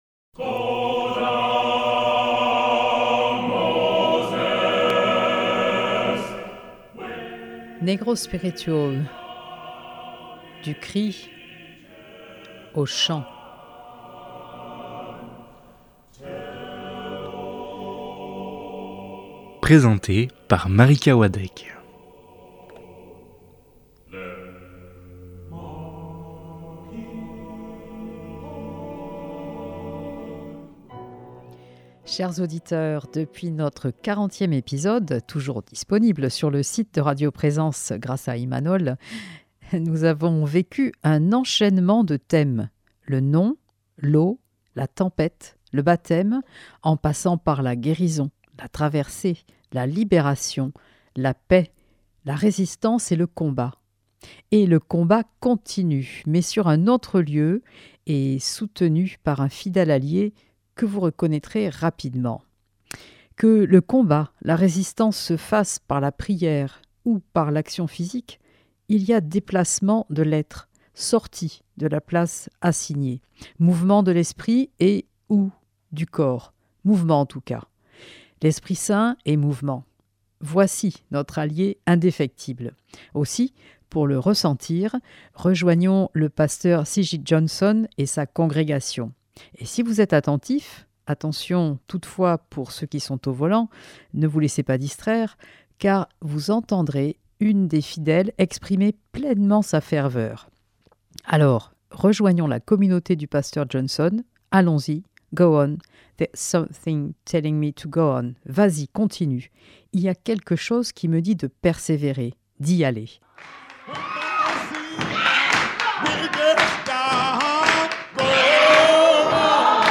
Productrice de Negro spiritual : du cri au chant